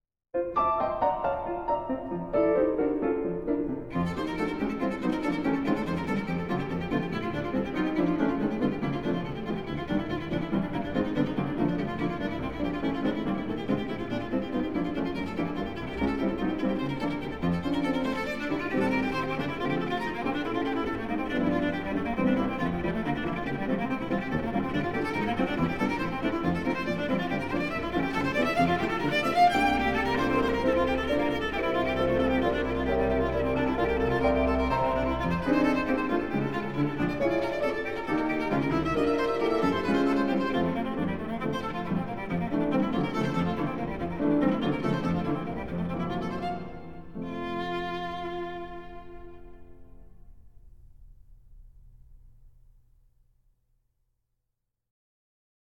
Viola
Piano